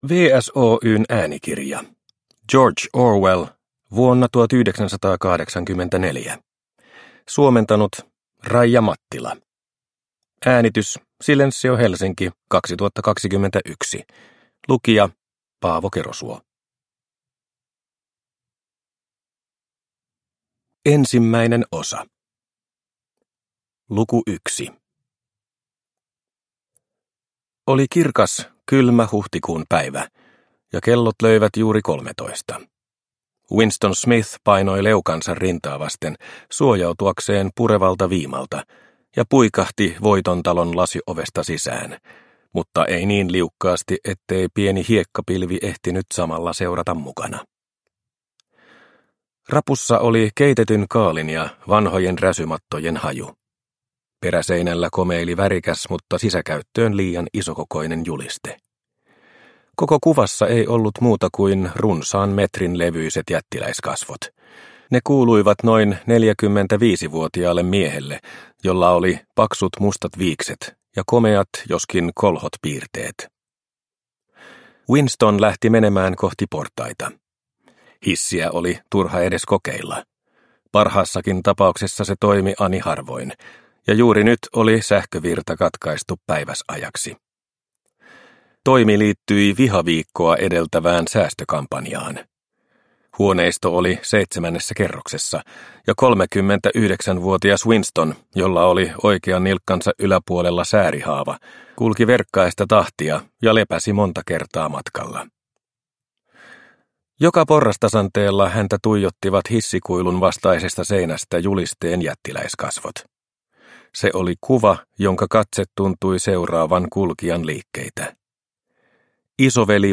Vuonna 1984 – Ljudbok – Laddas ner